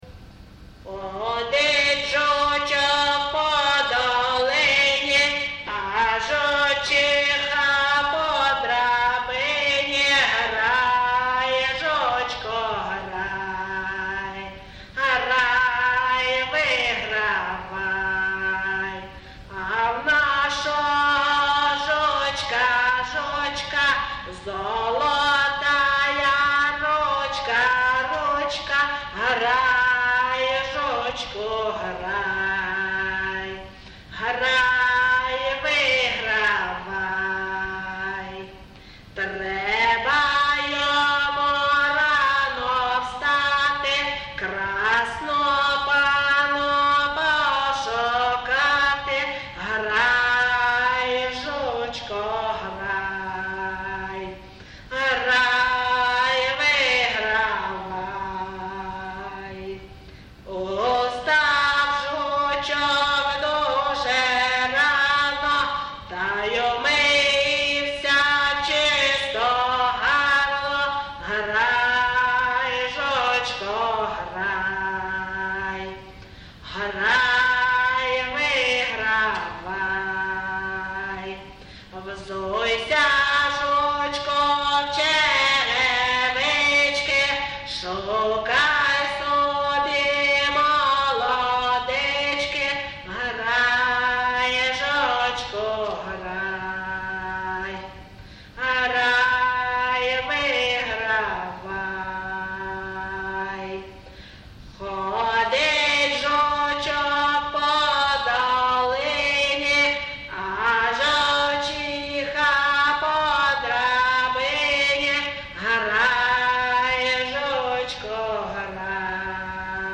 ЖанрВеснянки, Ігри
Місце записус. Ковалівка, Миргородський район, Полтавська обл., Україна, Полтавщина